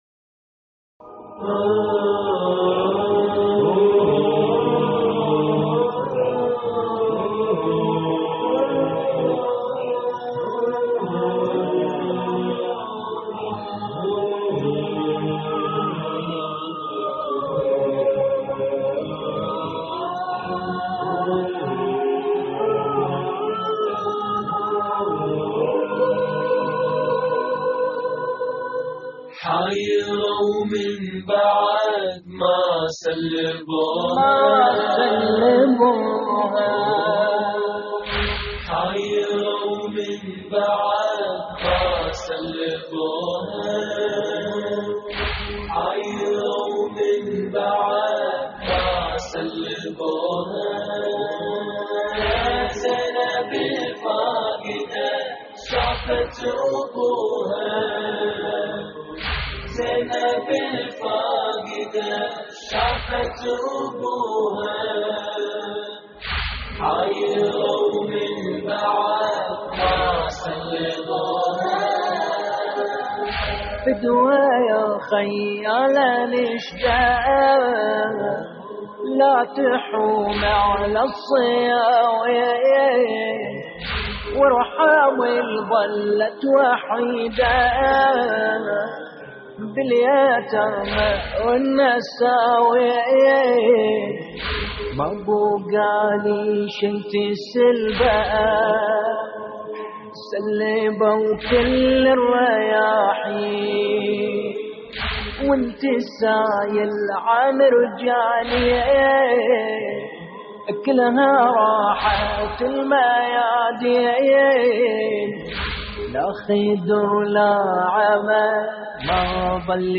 اللطميات الحسينية
موقع يا حسين : اللطميات الحسينية حايره ومن بعد ما سلبوها (غربة لقاء) - استديو «نينوى» لحفظ الملف في مجلد خاص اضغط بالزر الأيمن هنا ثم اختر (حفظ الهدف باسم - Save Target As) واختر المكان المناسب